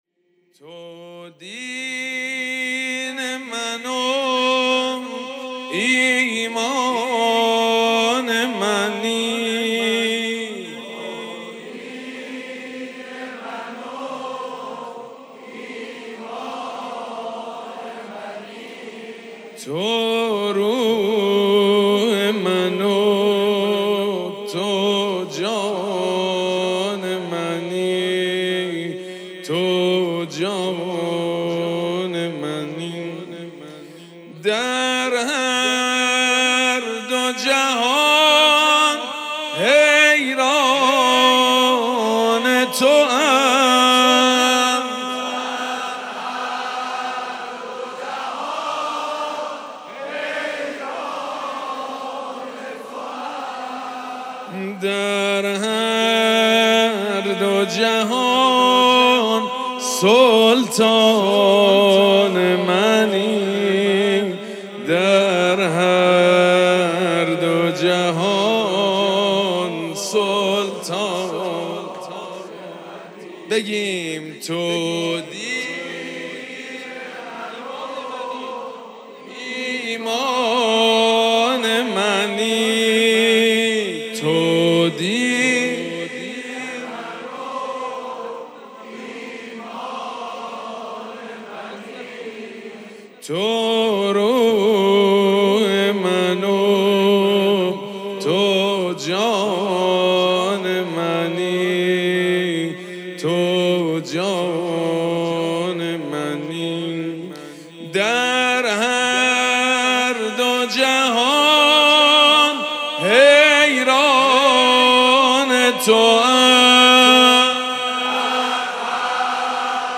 دانلود فایل صوتی شعر خوانی ولادت امام رضا (ع) 1404 تو دین من و ایمان منی حاج سید مجید بنی فاطمه - هیئت ریحانت الحسین (س)